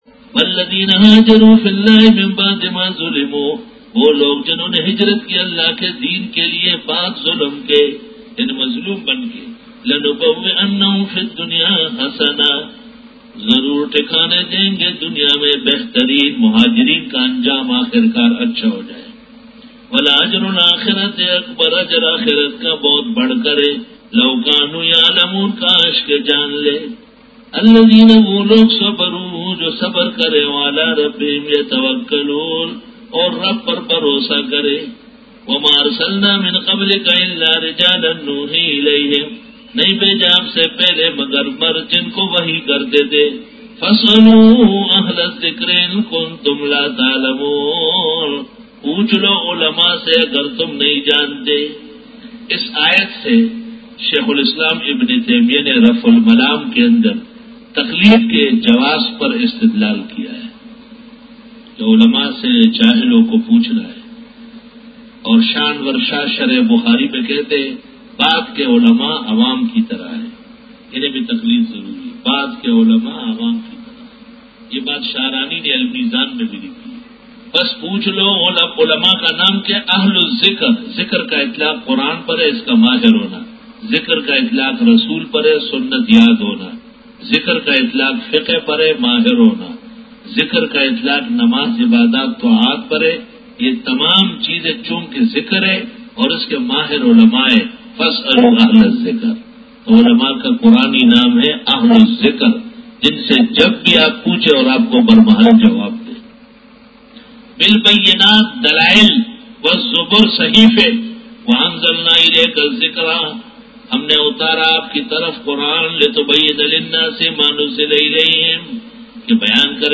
Dora-e-Tafseer